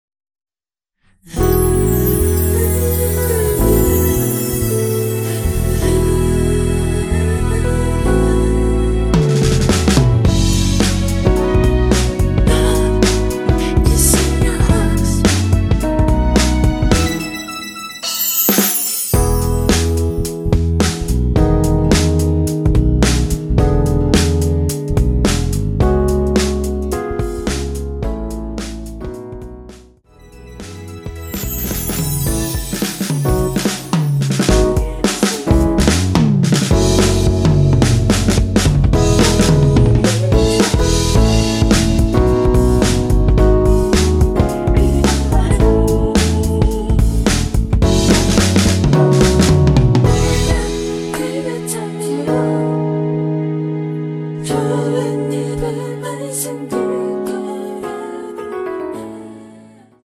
원키에서(-1)내린 코러스 포함된 MR입니다.
◈ 곡명 옆 (-1)은 반음 내림, (+1)은 반음 올림 입니다.
앞부분30초, 뒷부분30초씩 편집해서 올려 드리고 있습니다.